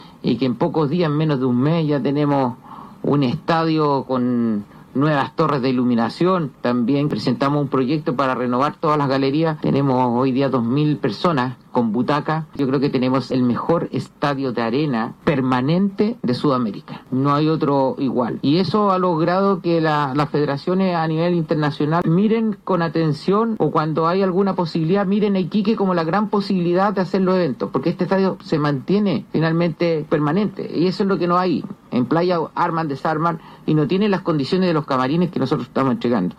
El alcalde de Iquique, Mauricio Soria, señaló que este campeonato es una forma de presentar oficialmente las nuevas instalaciones y destacar el impacto deportivo que traerá a la ciudad.